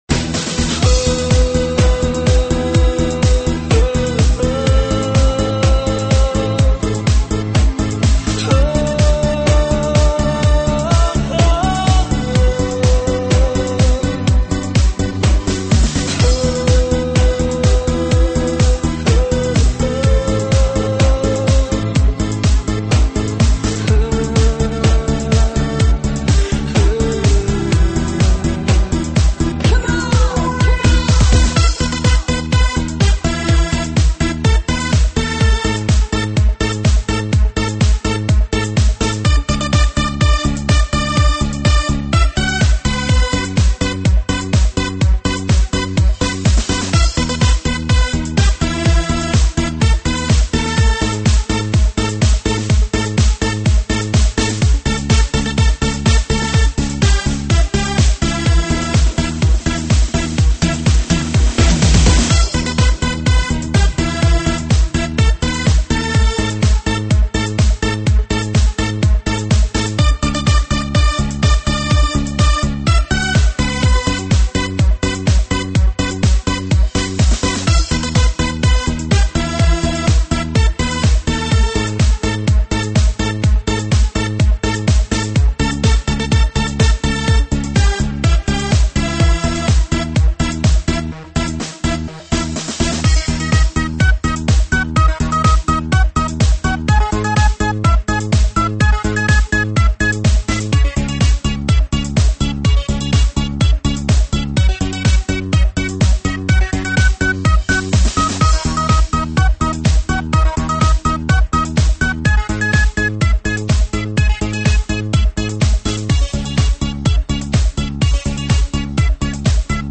栏目： 2009年度榜单